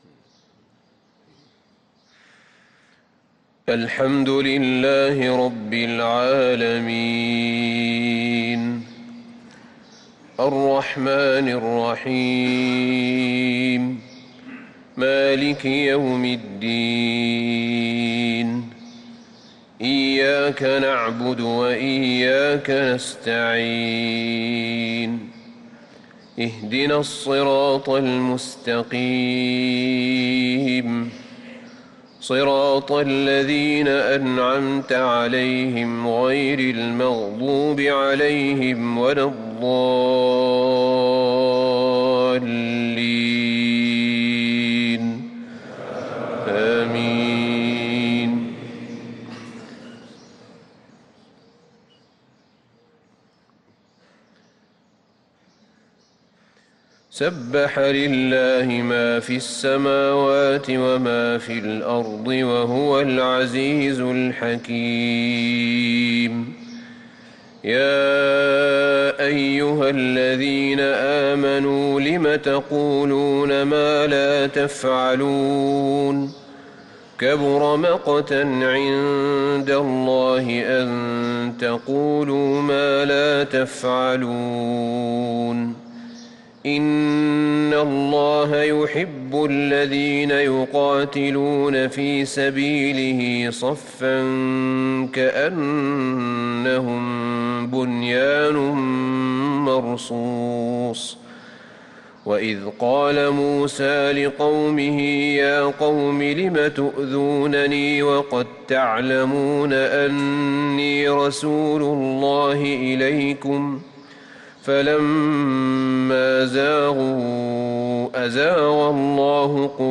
صلاة الفجر للقارئ أحمد بن طالب حميد 29 ربيع الآخر 1445 هـ